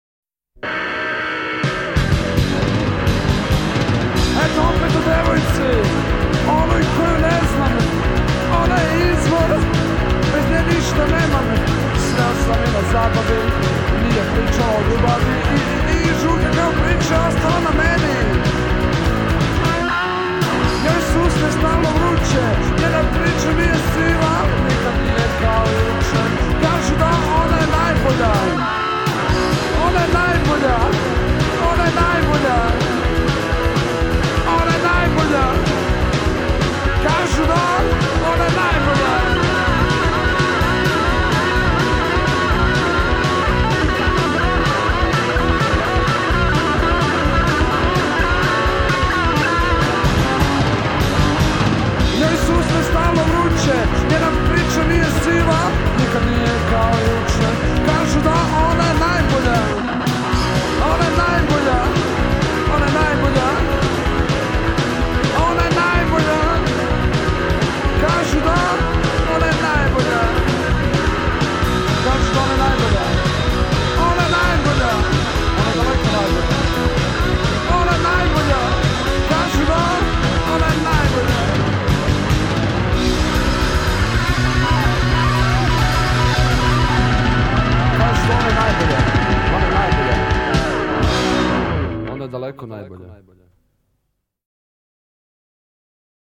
od ritma i bluza, do pank-roka.
Vokal
gitara
bas gitara
bubanj